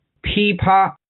噼啪/Pī pā/(Onomatopeya) sonido de crujidos etc.; pasos ligeros golpeteo.